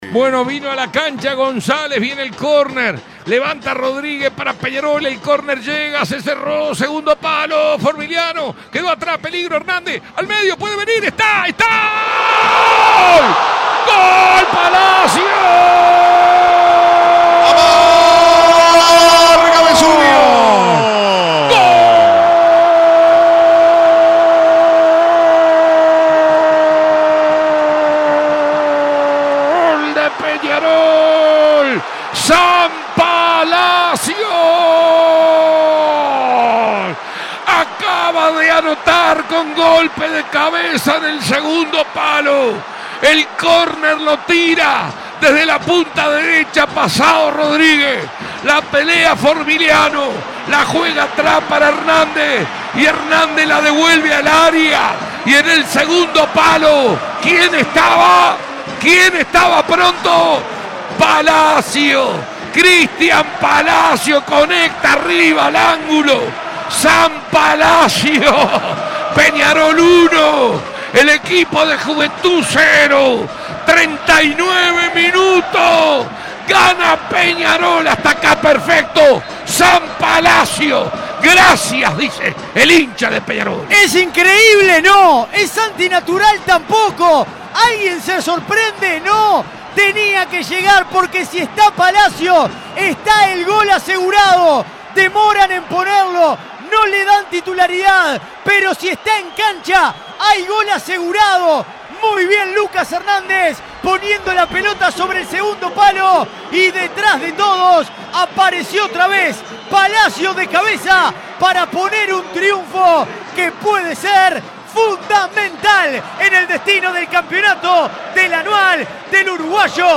relato del gol